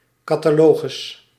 Ääntäminen
IPA: /ka.ta.lo.ɣʏs/